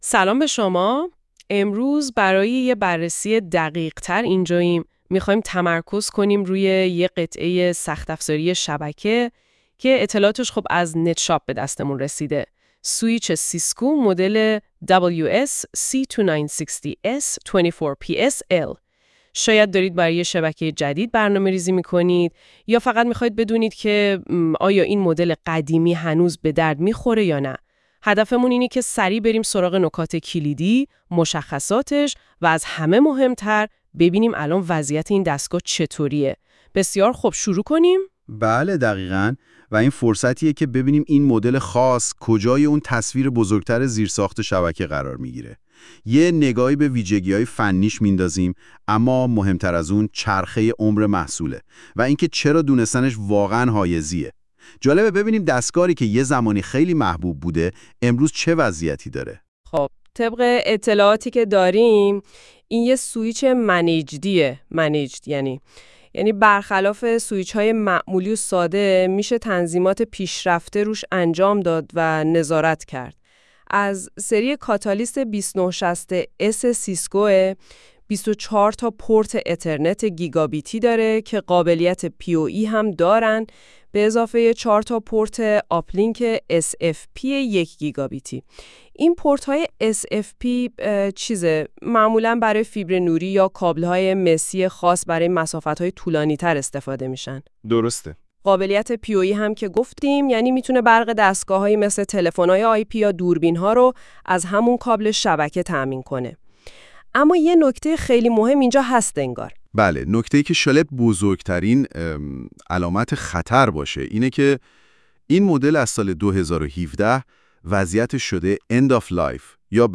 یرای راحتی شما نسخه صوتی توضیحات این محصول را آماده کرده ایم: